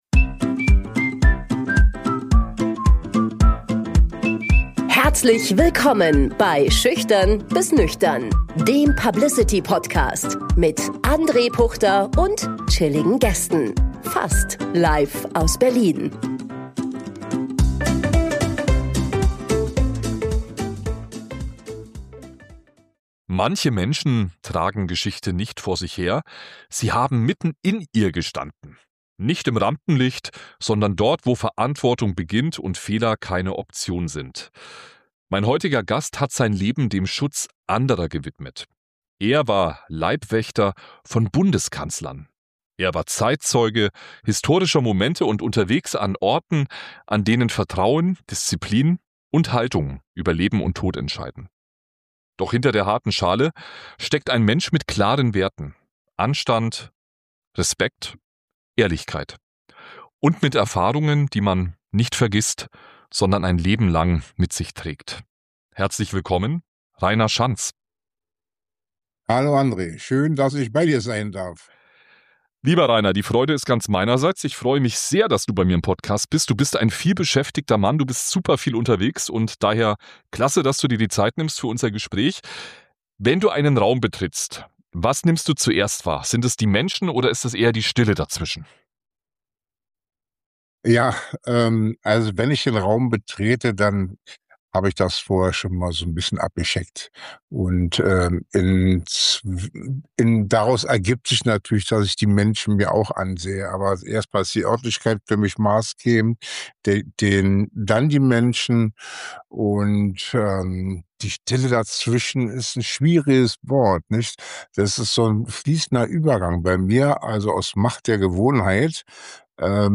Ein Gespräch über Verantwortung, Loyalität und die Erfahrungen, die man nicht vergisst – sondern ein Leben lang mit sich trägt.